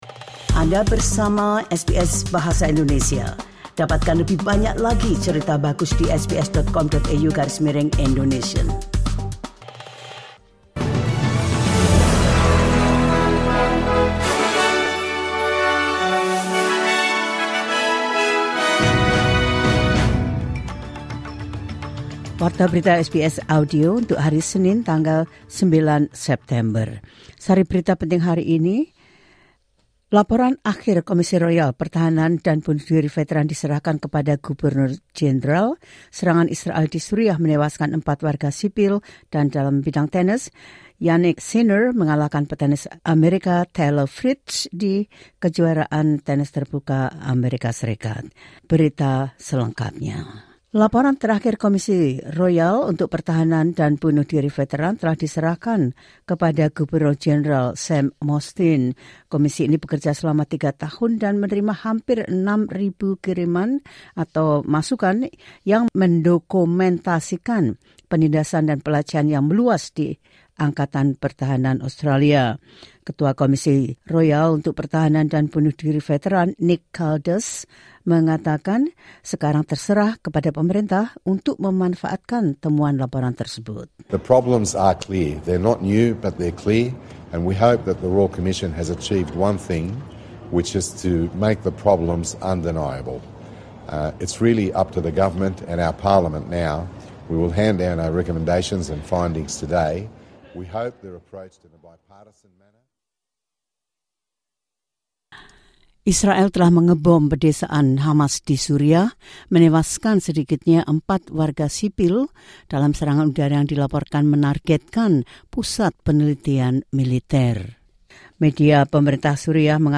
The latest news of SBS Audio Indonesian program – 09 Sep 2024